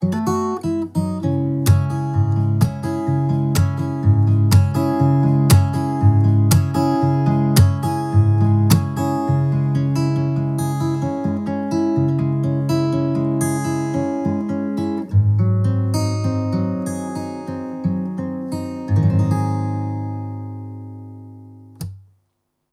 Zvukové zkoušky
Audio Technica AT4033 - gitara akustyczna